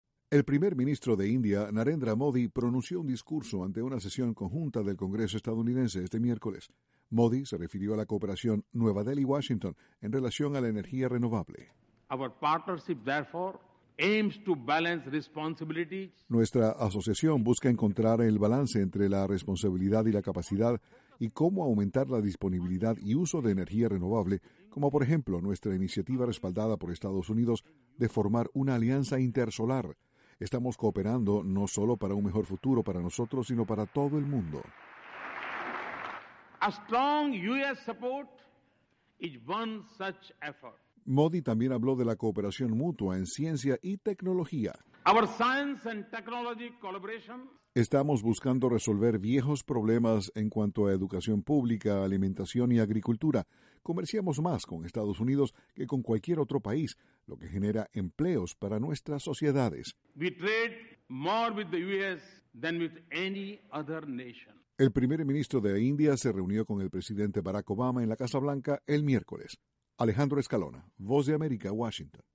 Educación pública y cooperación en materia energética fueron algunos de los temas que expuso el PM indio en el Congreso estadounidense. Desde la Voz de América, Washington